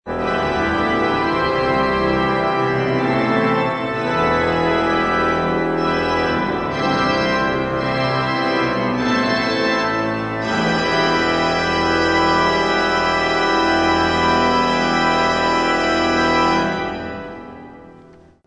Concert sur l'orgue Callinet de l'église Notre-Dame du Marthuret à Riom
Les extraits montrent quelques échantillons des sonorités particulières de l'orgue.